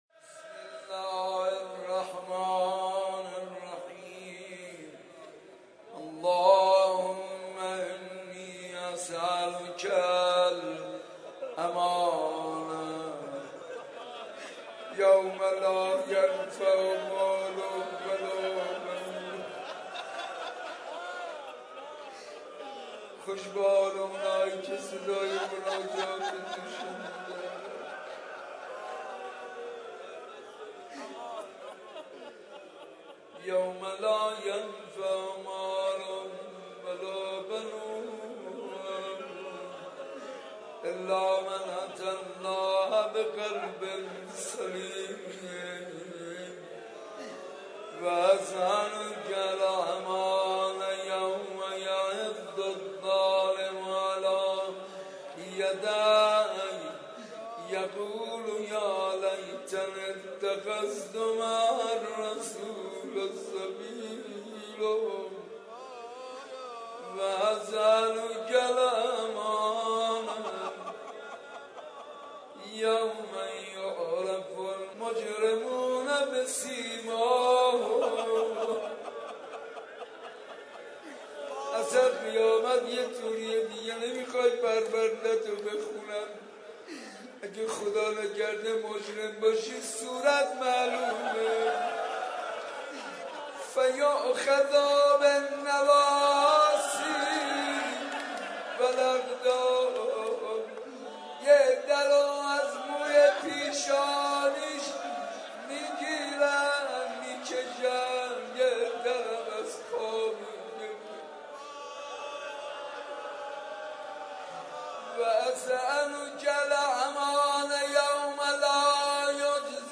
شب بیست و دوم ماه مبارک رمضان93
بخش اول:مناجات بخش دوم:دعای ابوحمزه